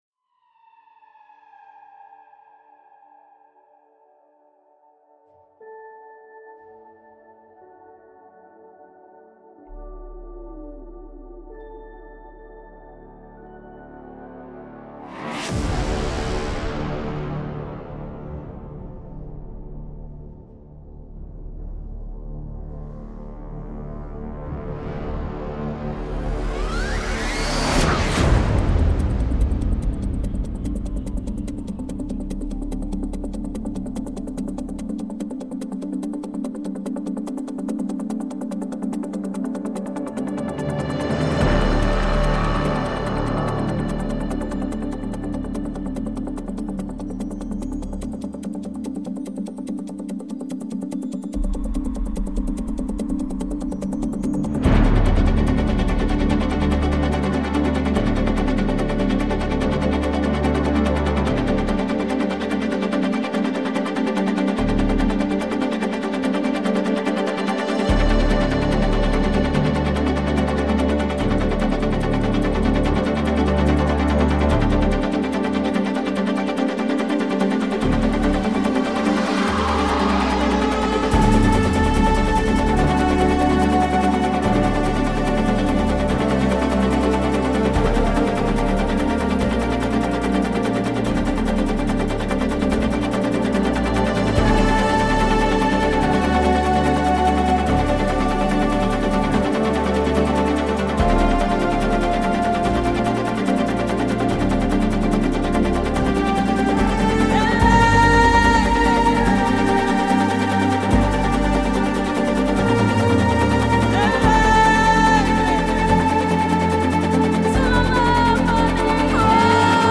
• 4种核心乐器类（鼓，混合，管弦乐，合成器）
8Dio Terminus是一款专业级工具，适用于史诗般的大片乐谱和预告片，其中包含1200多种定制的电影乐器和FX。
总站的声音大，现代，录音棚干净，所有东西都精益求精。
所有四个核心组均包含七个存储库，分别是Hits，Slams，Sub Hits，Short and Long Falls和Short and Long Risers。